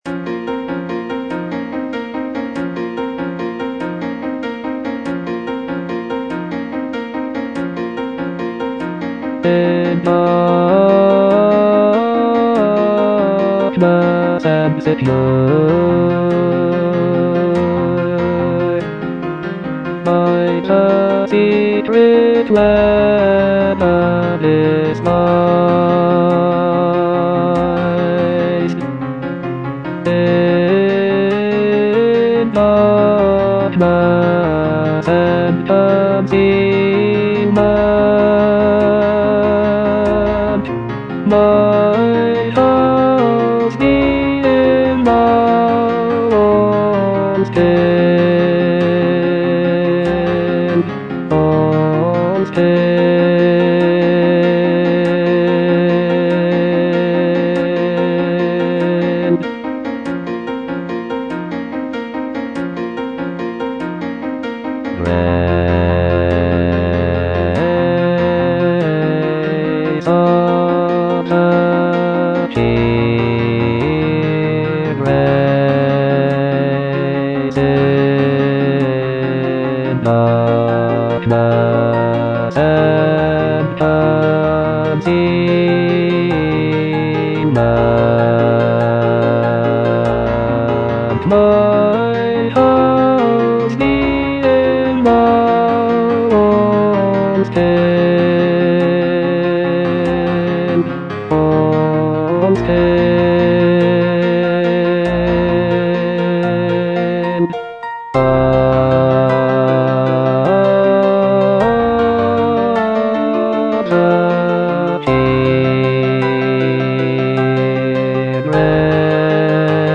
(bass I) (Voice with metronome) Ads stop
choral work